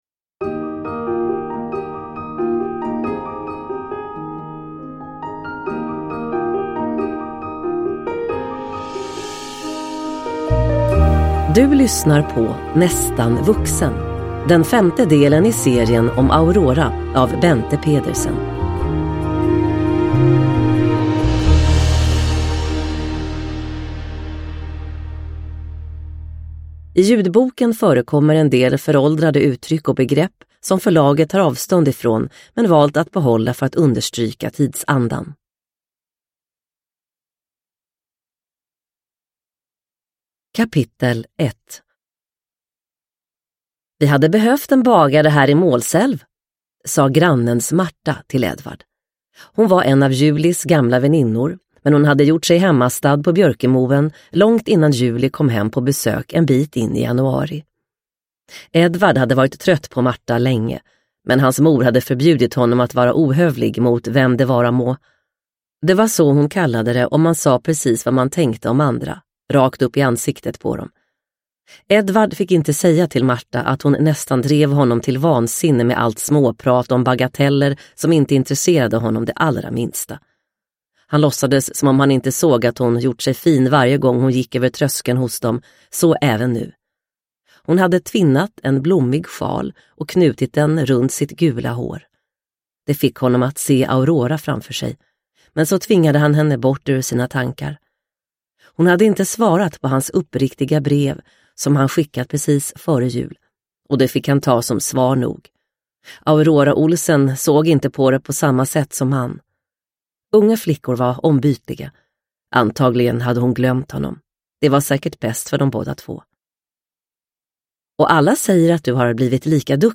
Nästan vuxen – Ljudbok – Laddas ner